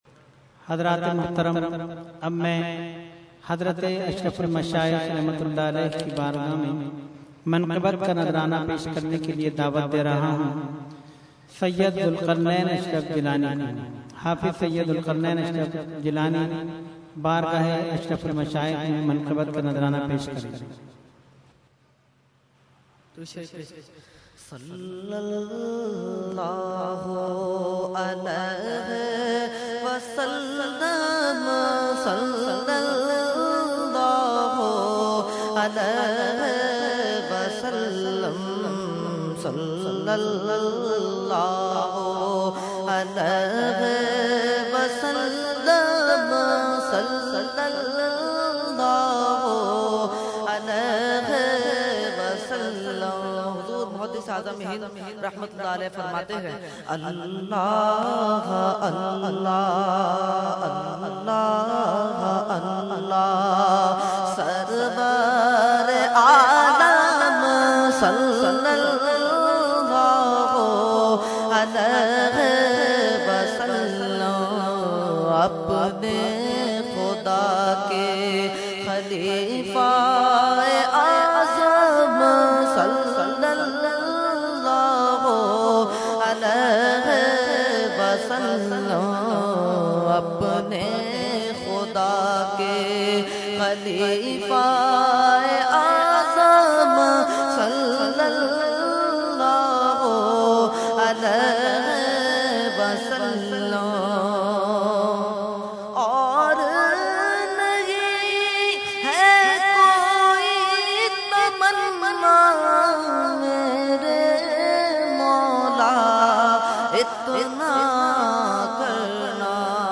Category : Manqabat | Language : UrduEvent : Urs Ashraful Mashaikh 2012